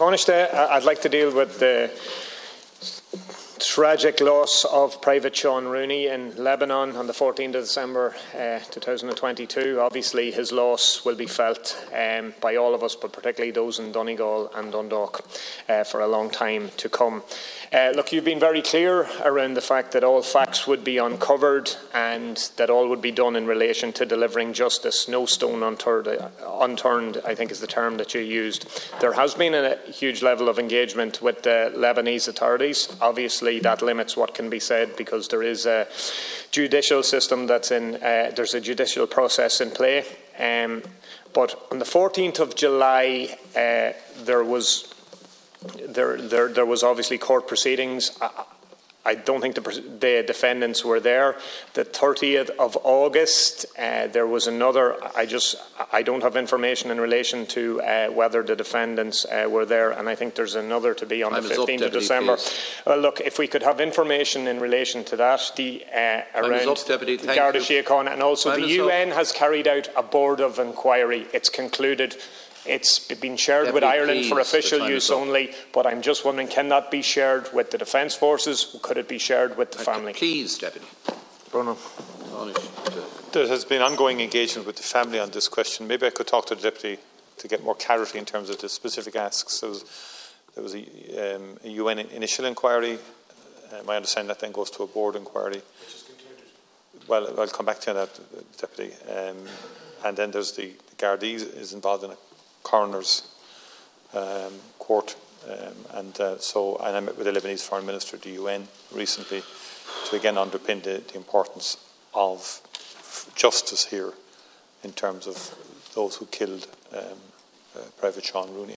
In response to Deputy O’Murchu in the Dail, Tanaiste Micheal Martin said he has raised the matter with the Lebanese Foreign Minister for the UN: